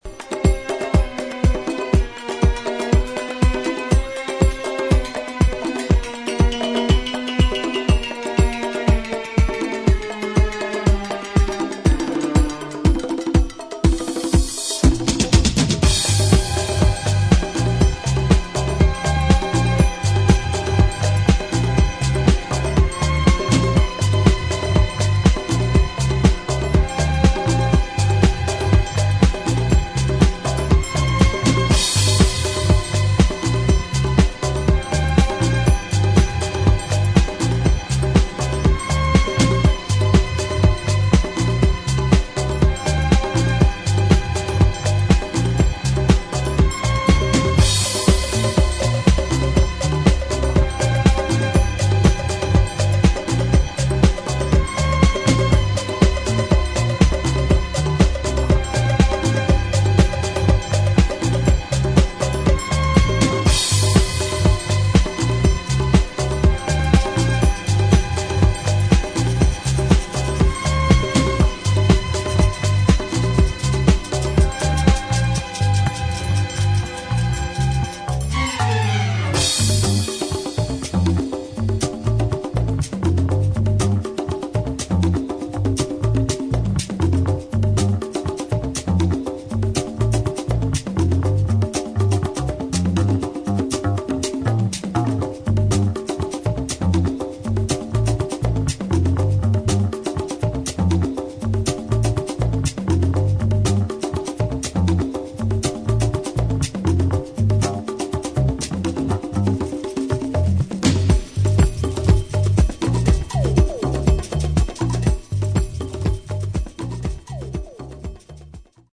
[ DISCO / BRAZIL ]